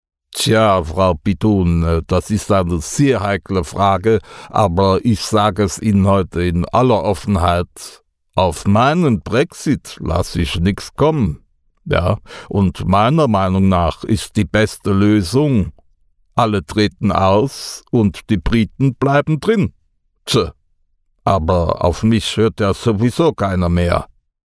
Spreezeitung-Kohl-Brexit.mp3